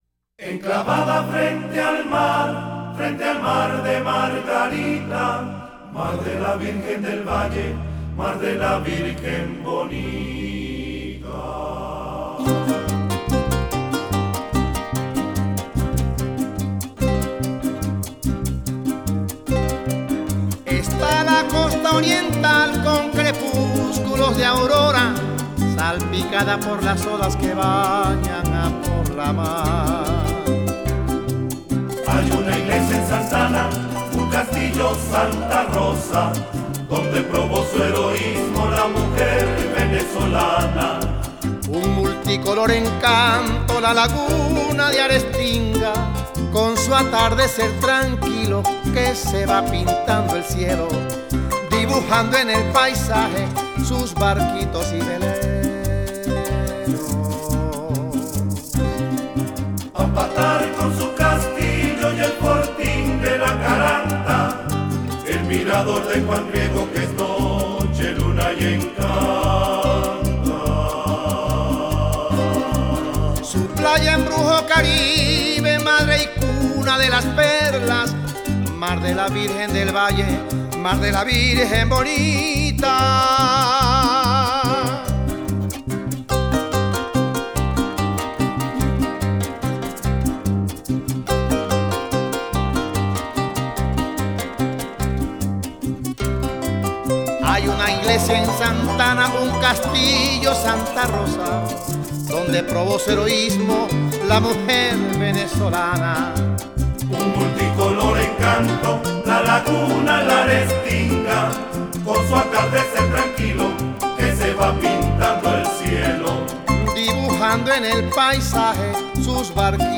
1970   Genre: Latin   Artist